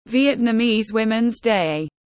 Ngày phụ nữ Việt Nam tiếng anh gọi là Vietnamese Women’s Day, phiên âm tiếng anh đọc là /ˌvjɛtnəˈmiːz ˈwɪmənz deɪ/
Vietnamese Women’s Day /ˌvjɛtnəˈmiːz ˈwɪmənz deɪ/
Vietnamese-Womens-Day-.mp3